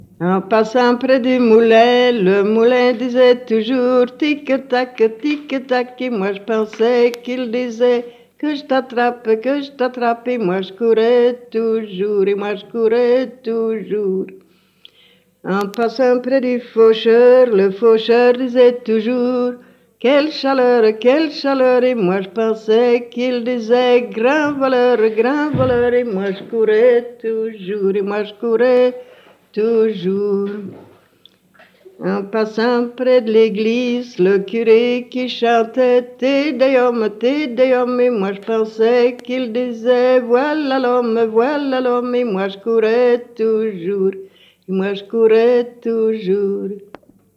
Genre : chant
Type : chanson narrative ou de divertissement
Interprète(s) : Anonyme (femme)
Lieu d'enregistrement : Surice
Support : bande magnétique